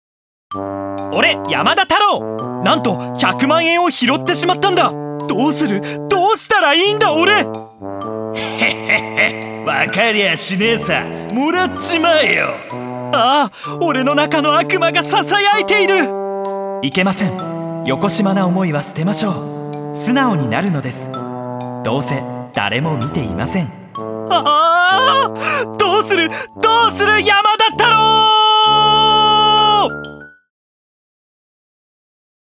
● 自己紹介● ボイスサンプル01● ボイスサンプル02● ボイスサンプル03● ボイスサンプル04● ボイスサンプル05● ボイスサンプル06● ボイスサンプル07● ボイスサンプル08● ボイスサンプル09● ボイスサンプル10